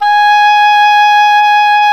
WND OBOE G#5.wav